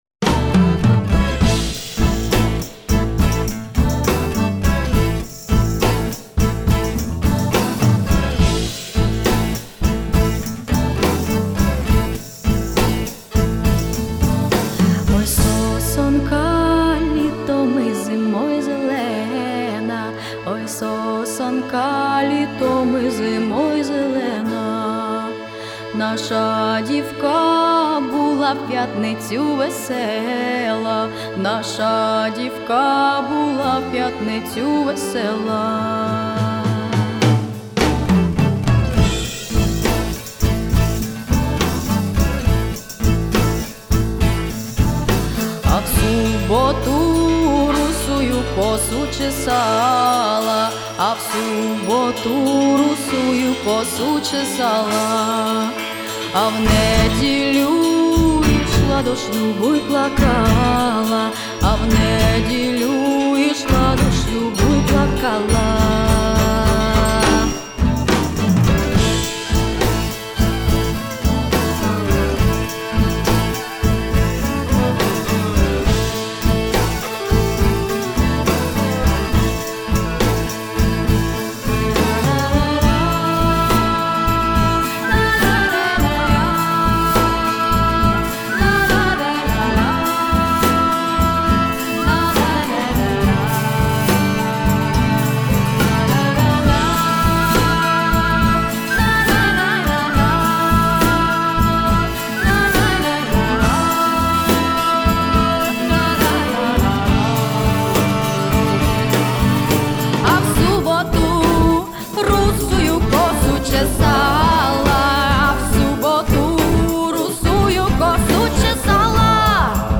Етно
співачка
фольк